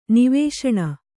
♪ nivēśaṇa